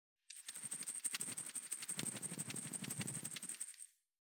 353塩を振る,調味料,カシャカシャ,サラサラ,パラパラ,ジャラジャラ,サッサッ,ザッザッ,シャッシャッ,シュッ,パッ,サッ,トントン,カラカラ,
効果音厨房/台所/レストラン/kitchen